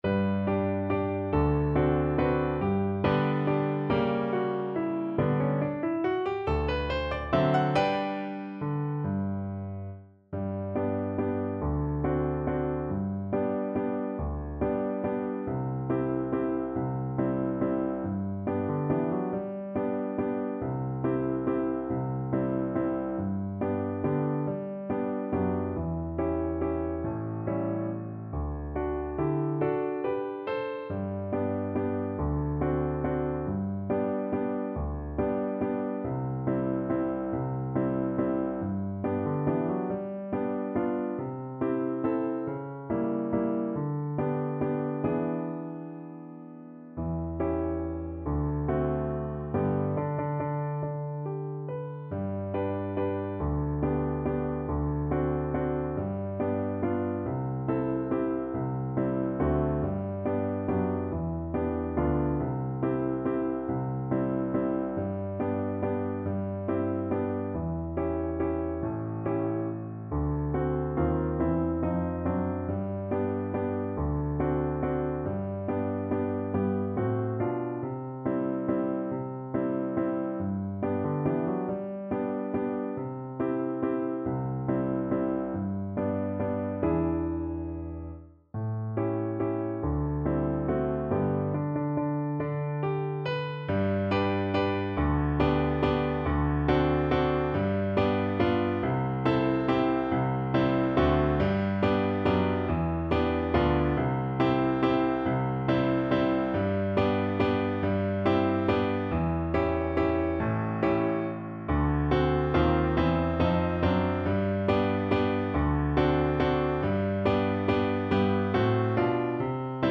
3/4 (View more 3/4 Music)
~ = 140 Tempo di Valse